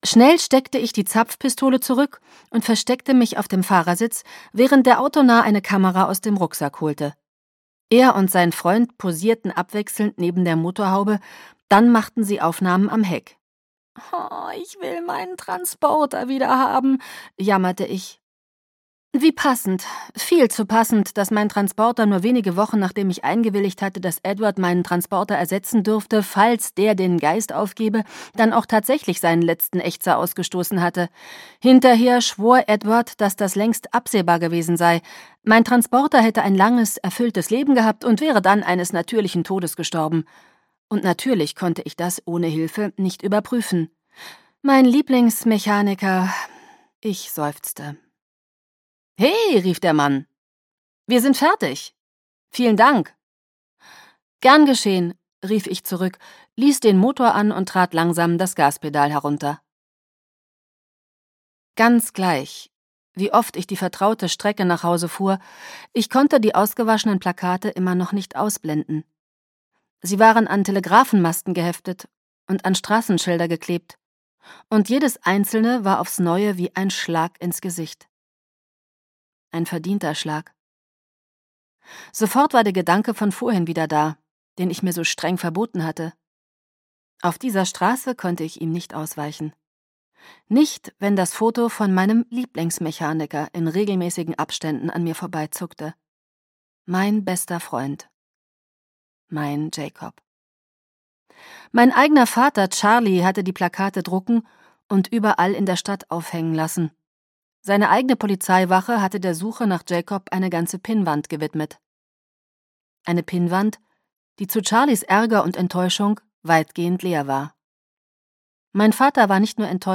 Bella und Edward 4: Biss zum Ende der Nacht - Stephenie Meyer - Hörbuch